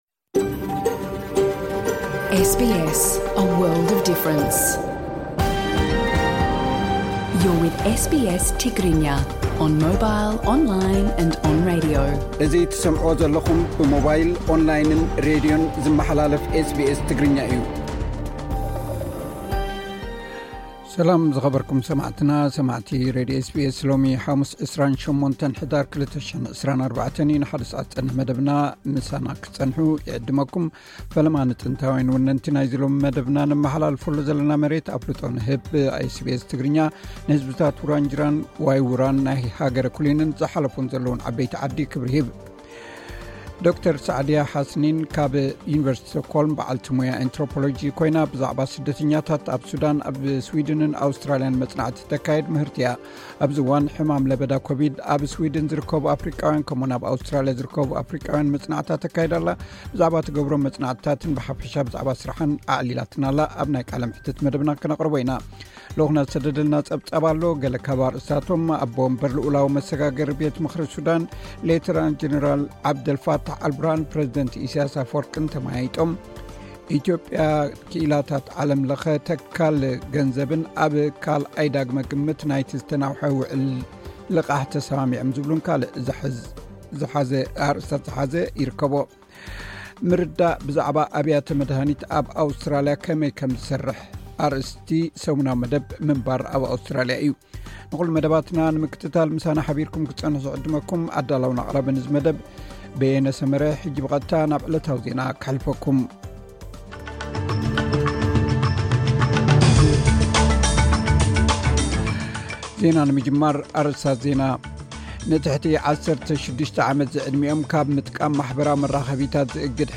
ብዛዕባ ትገብሮም መጽናዕትታትን ብሓፈሻ ብዛዕባ ስርሓን ኣዕሊላትና ኣላ።